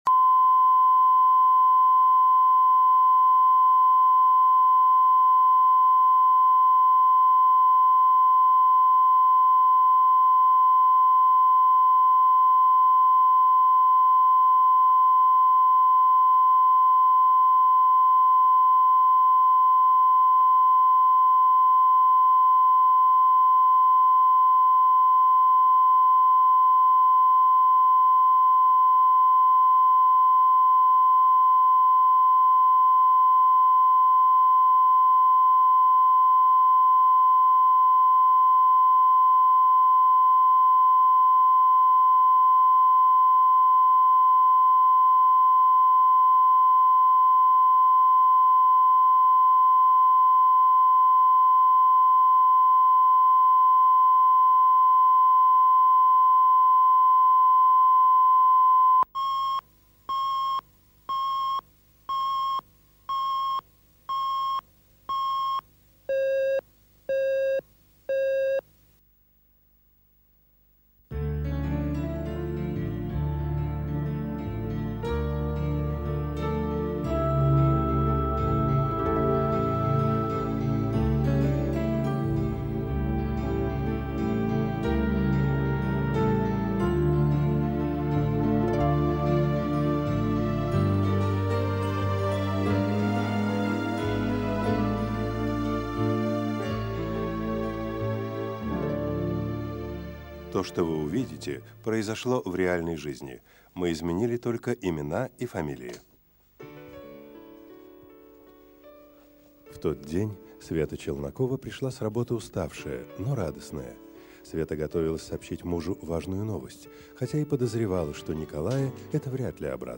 Аудиокнига Наследник